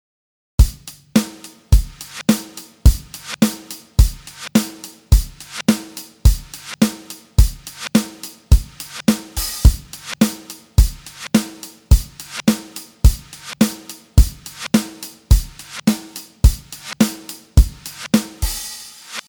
インサートにかけるのではなく、AUXトラックを作ってパラレルで使ってみましょう。
フィルターをかけてローを削って、LFOでパンに周期的なエフェクトをかけてみました。
ヘッドフォンだとわかりやすいかな？